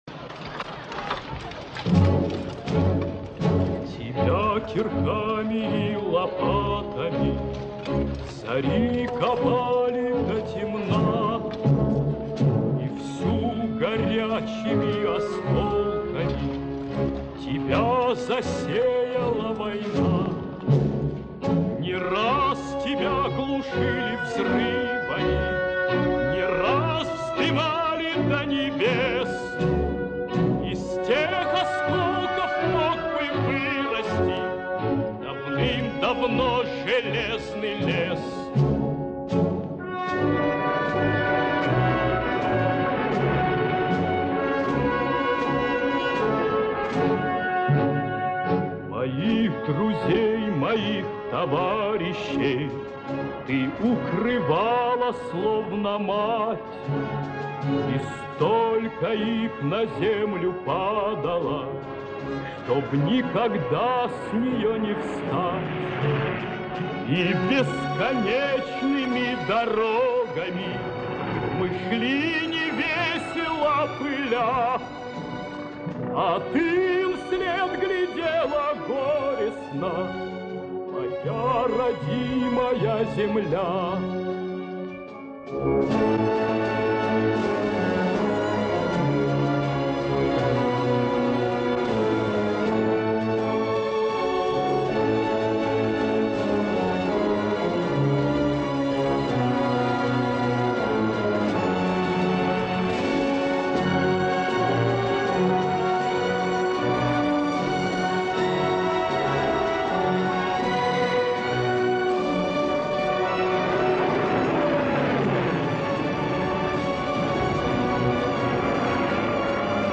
Повышение качества.